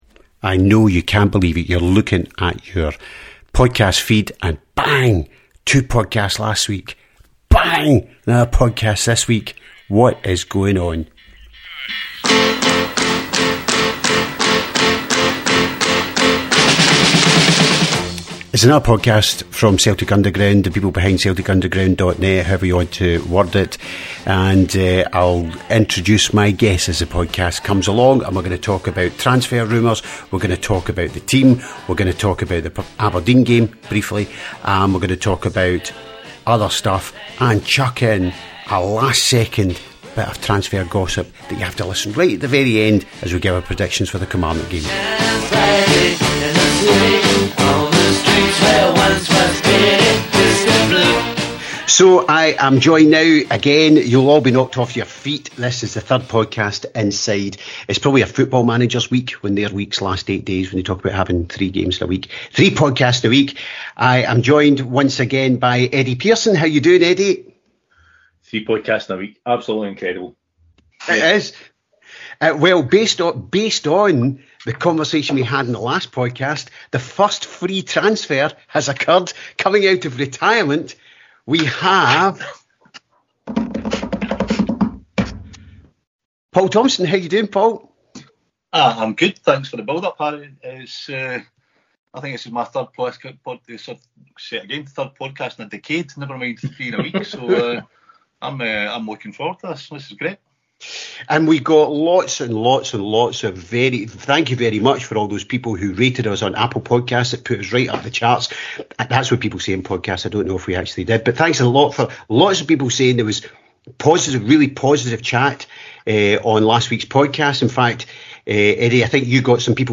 Headliner Embed Embed code See more options Share Facebook X Subscribe Sense and sensibility Podcast No1 of the new season had 2 of us, podcast No2 had 3 of us and this week, podcast No3 we’ve got 4 (four) of us on it!
This weeks’ podcast is not as long as last week’s marathon but it’s still an hour of Celtic chat by middle aged Celtic fans - just what we all need on a regular basis.